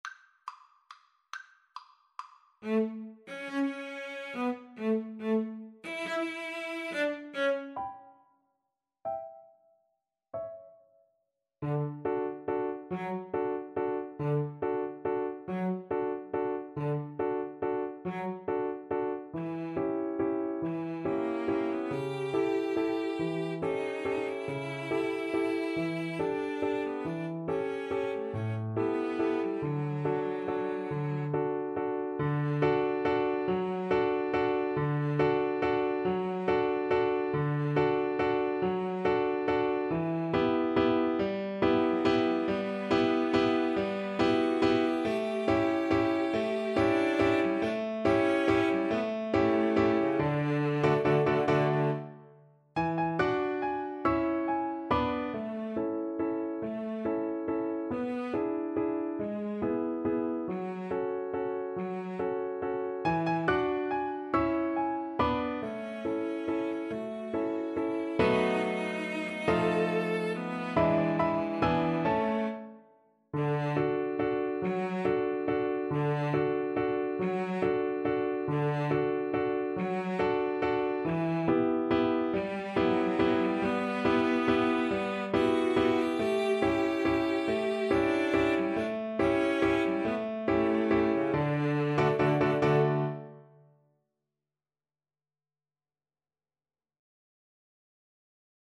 ~ = 140 Tempo di Valse
3/4 (View more 3/4 Music)
Piano Trio  (View more Intermediate Piano Trio Music)